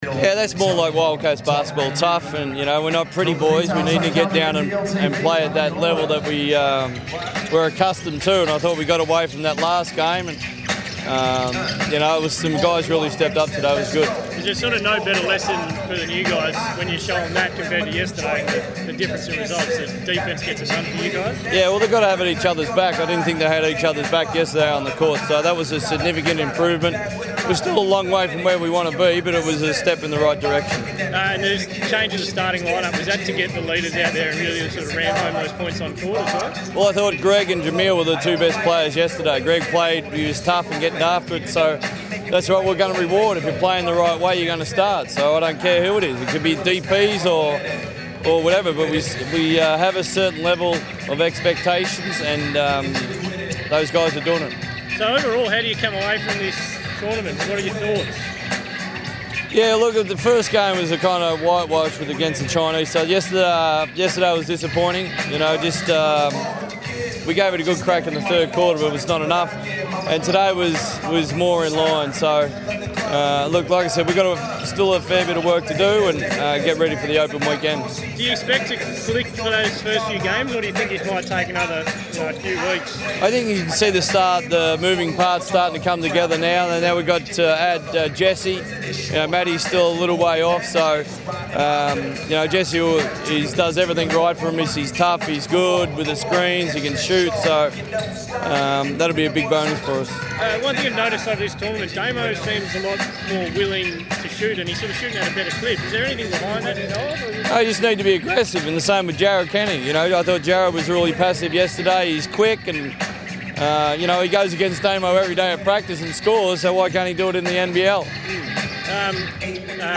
spoke to media after the Wildcats final pre-season game.